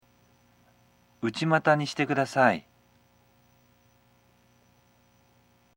Contoh Kalimat
dari staf kepada pasien/klien